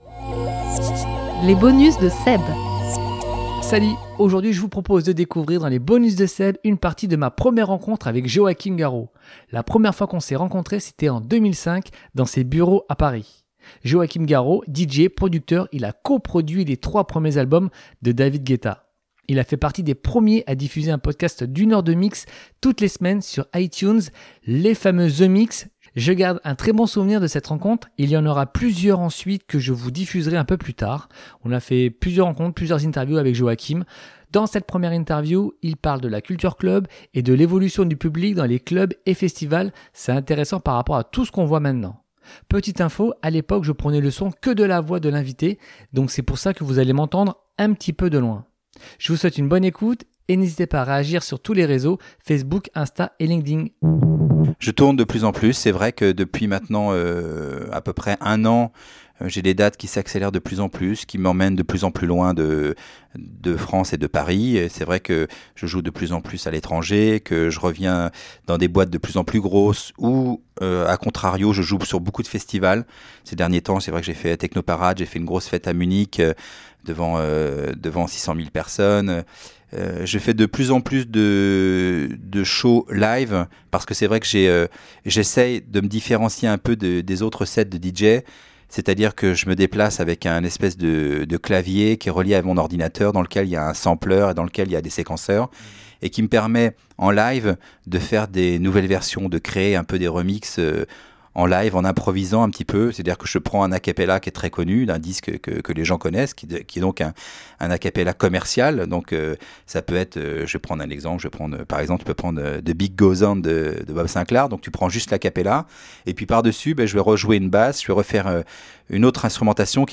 Dans cet épisode il parle de la culture Club et de l’évolution du public dans les Clubs et Festivals … Petite info à l’époque, je prenais le son que de la voix de l’invité donc c’est pour ça que vous allez m’entendre de loin dans l’interview…Bonne écoute à tous.